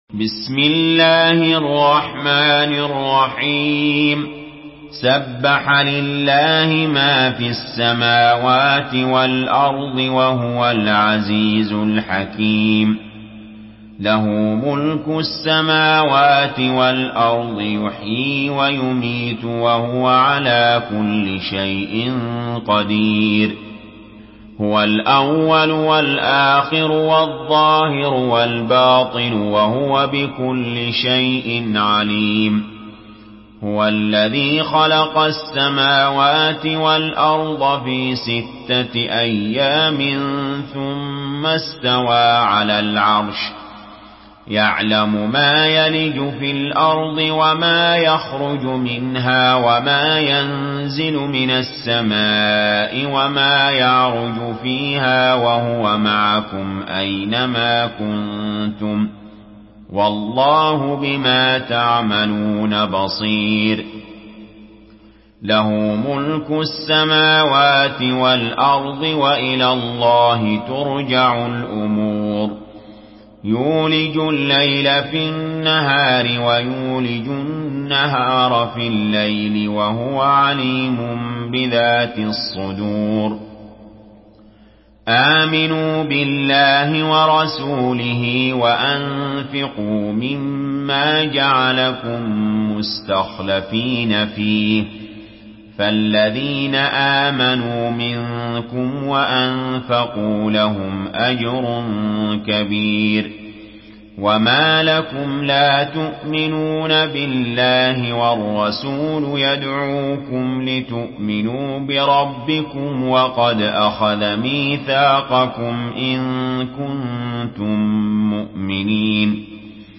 Surah Al-Hadid MP3 by Ali Jaber in Hafs An Asim narration.
Murattal Hafs An Asim